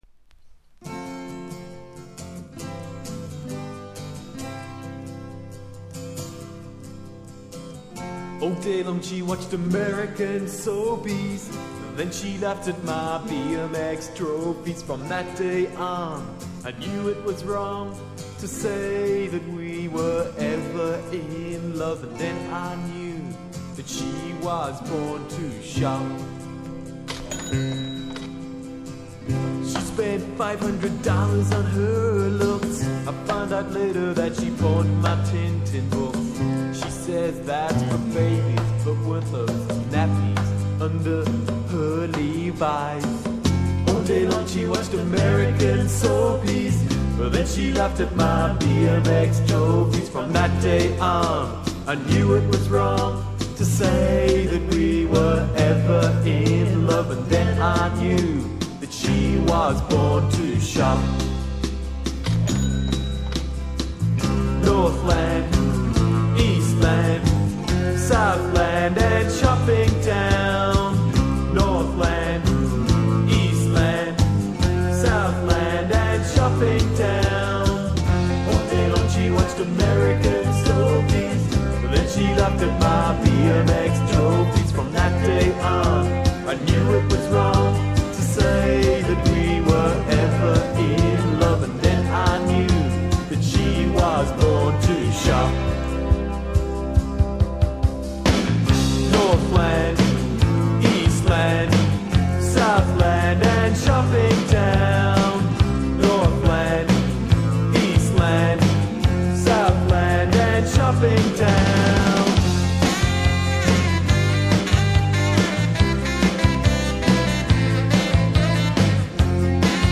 80'S - 90'S RARE NEO ACOUSTIC LISTS  (A-D)
ソフティケイトされたイントロから徐々にデルモンテ汁が溢れ、サビでホーンも入る問答無用の青春 ネオアコ♪